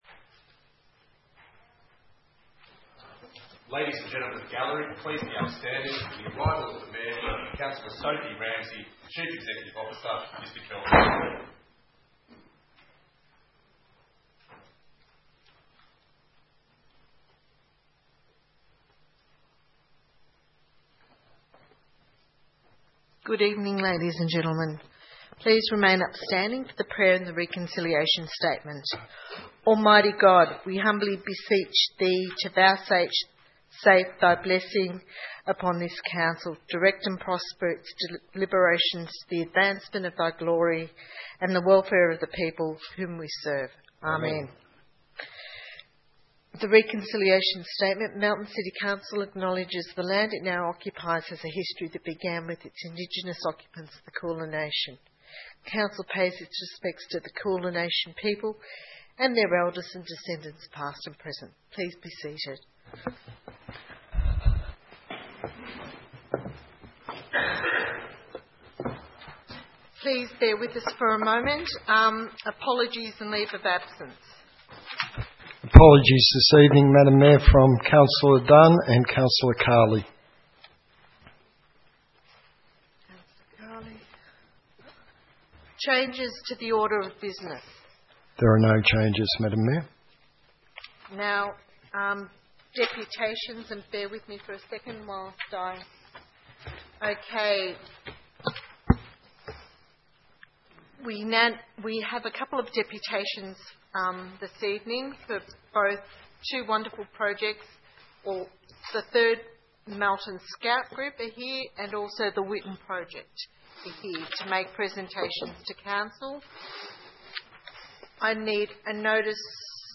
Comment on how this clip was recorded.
13 October 2015 - Ordinary Council Meeting